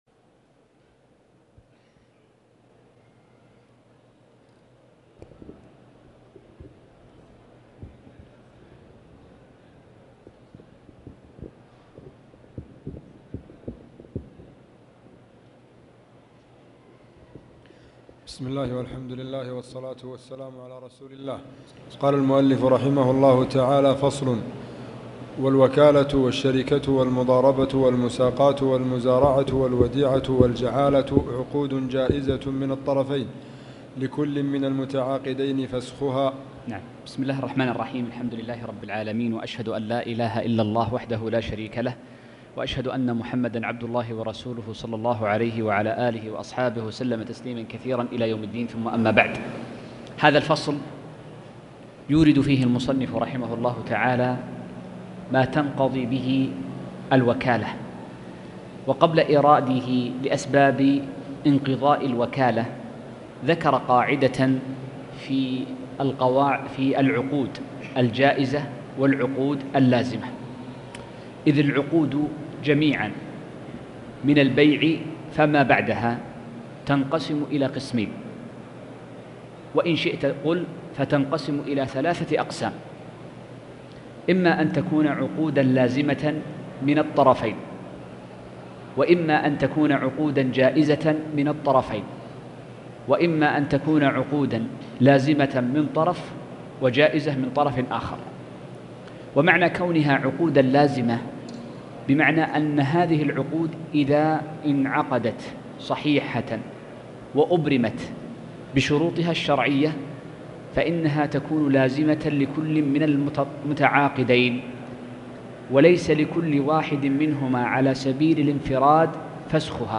تاريخ النشر ١٨ جمادى الآخرة ١٤٣٨ هـ المكان: المسجد الحرام الشيخ